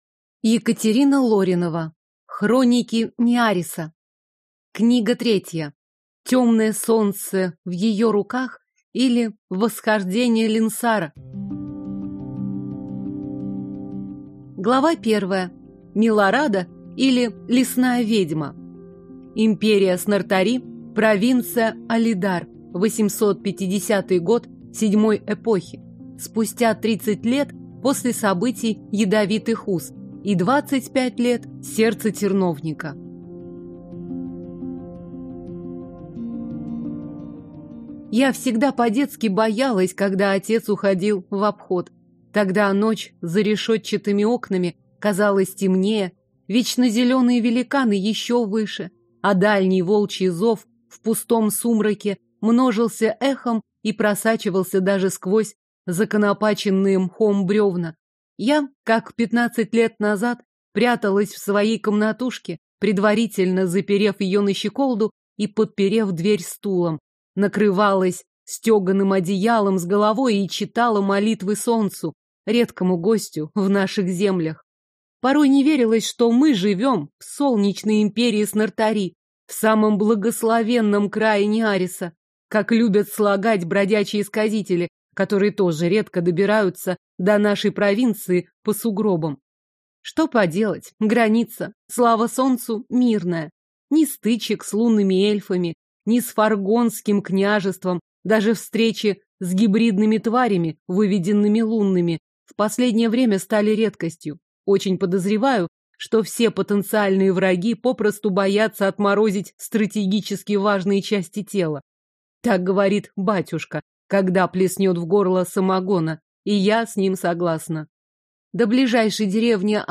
Аудиокнига Темное солнце (в ее руках), или Восхождение Ленсара | Библиотека аудиокниг